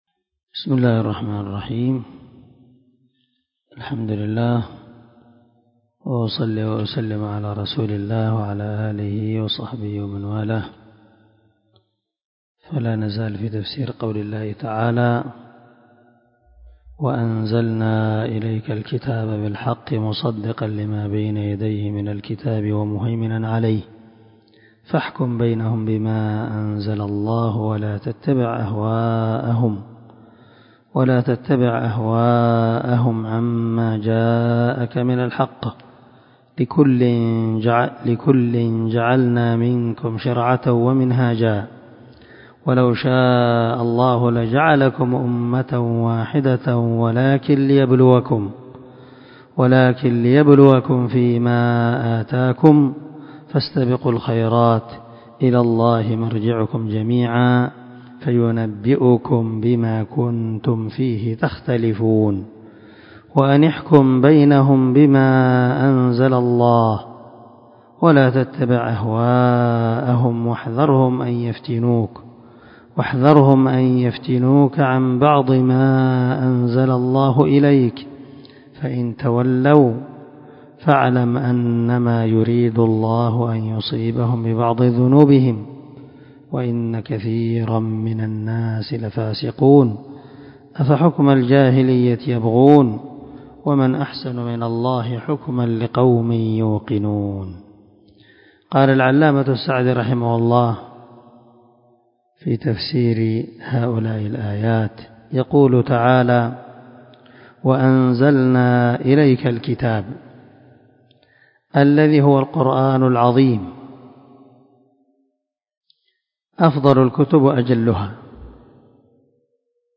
367الدرس 33 تابع تفسير آية ( 48 – 50 ) من سورة المائدة من تفسير القران الكريم مع قراءة لتفسير السعدي